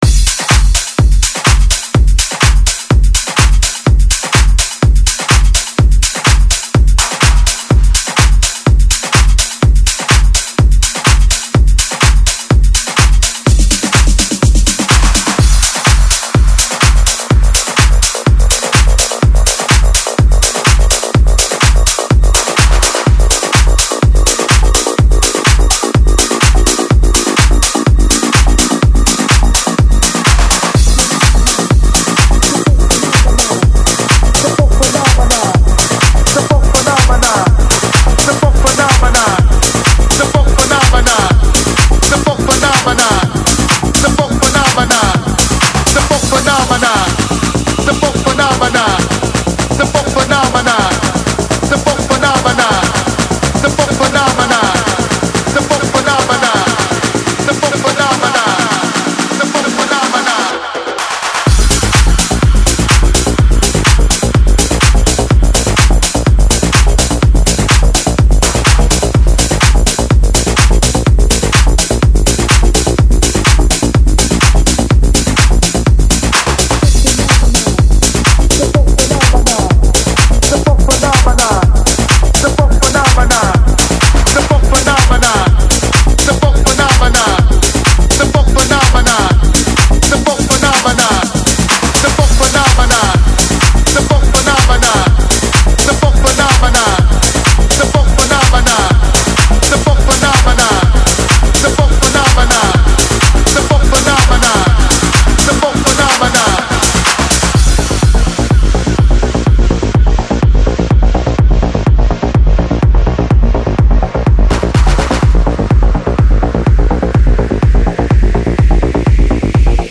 Club House, Disco Издание